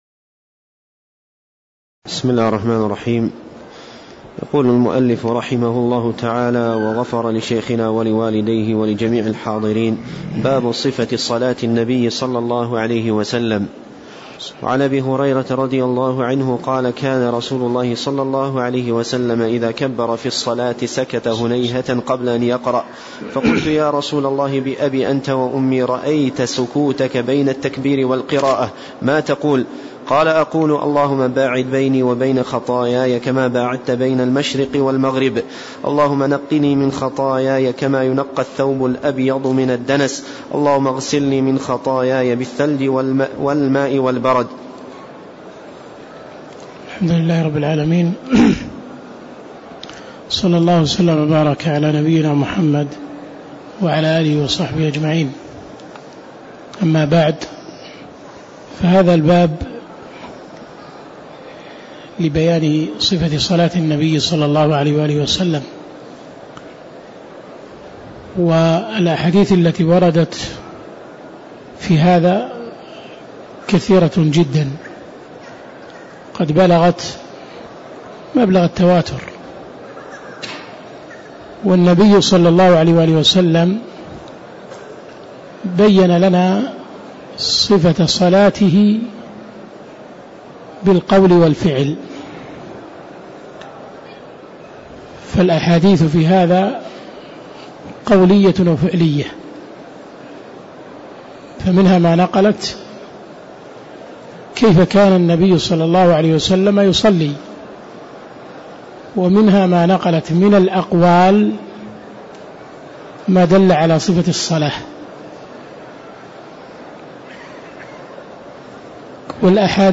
تاريخ النشر ٢٩ رجب ١٤٣٦ هـ المكان: المسجد النبوي الشيخ